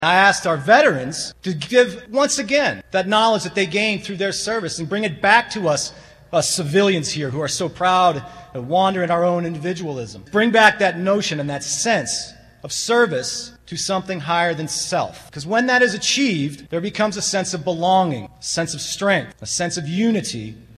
Manhattan pauses for Veterans Day activities